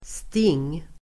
Uttal: [sting:]